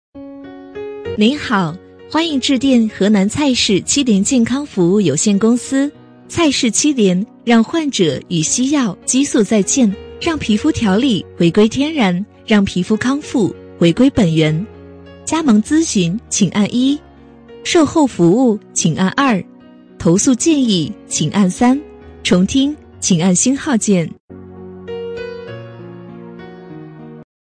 【女57号彩铃】服务有限公司
【女57号彩铃】服务有限公司.mp3